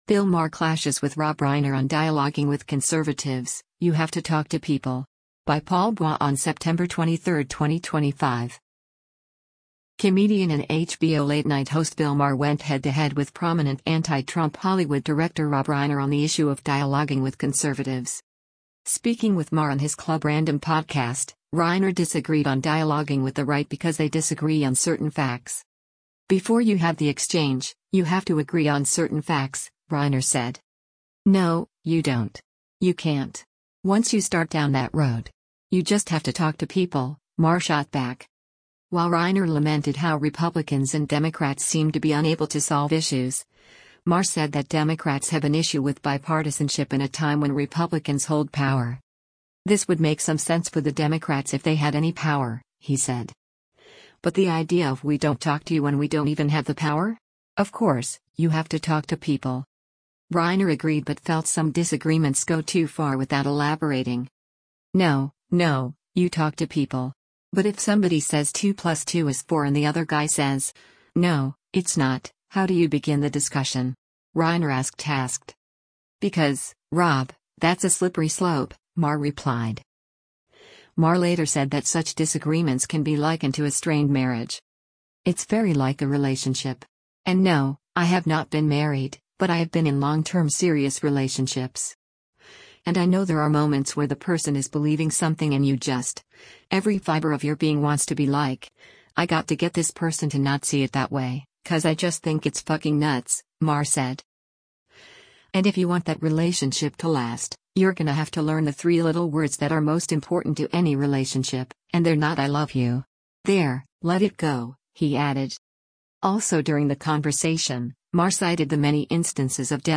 Speaking with Maher on his Club Random podcast, Reiner disagreed on dialoguing with the right because they disagree on certain facts.